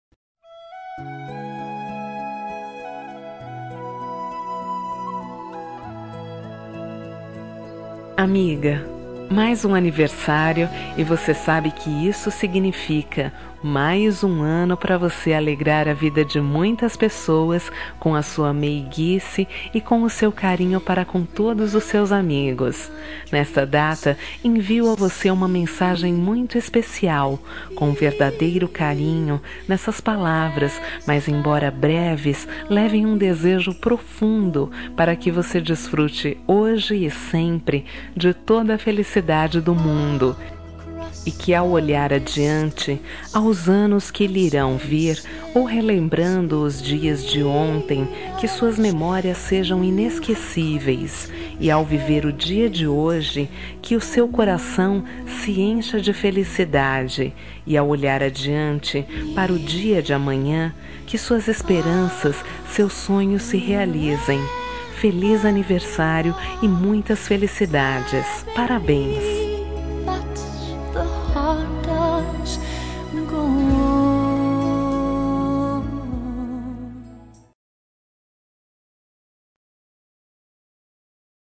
Telemensagem Aniversário de Amiga – Voz Feminina – Cód: 1521